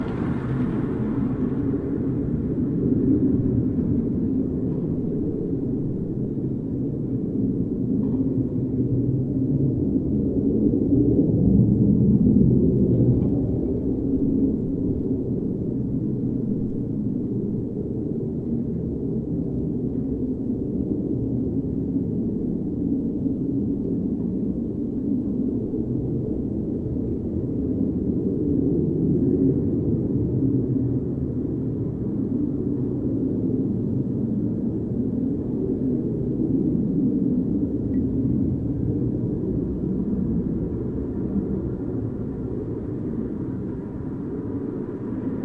城市街道" 哼
Tag: 音景 环境 背景 氛围 现场录音 环境 气氛 晚上 噪声 飞机 飞机─引擎 测试 哼哼 火箭 工业 城市